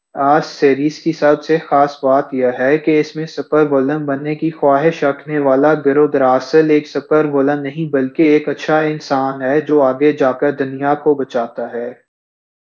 Spoofed_TTS/Speaker_13/103.wav · CSALT/deepfake_detection_dataset_urdu at main